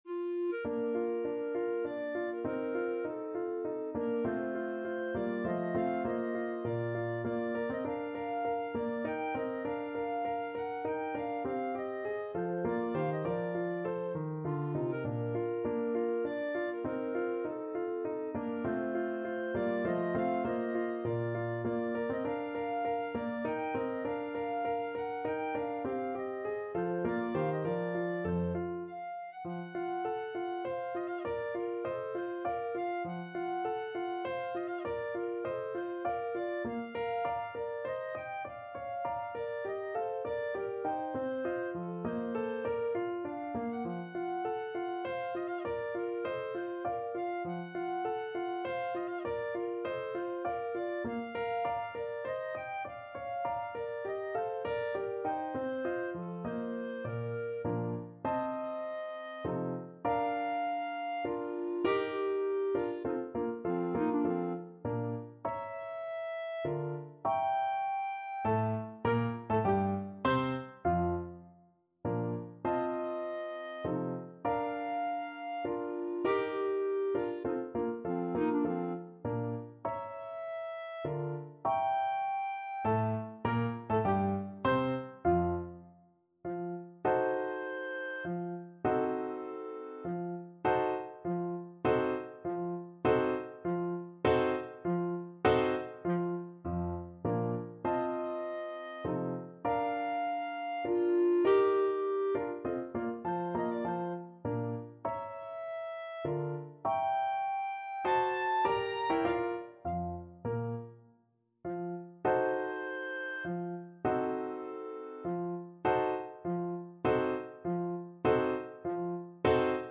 Menuetto Moderato e grazioso
3/4 (View more 3/4 Music)
F4-Bb6
Classical (View more Classical Clarinet Music)